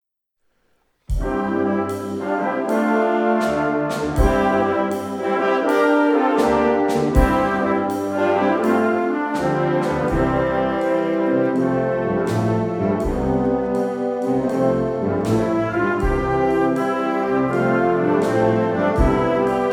Kategorie Blasorchester/HaFaBra
Unterkategorie Konzertmusik
Besetzungsart/Infos 4part; Perc (Schlaginstrument)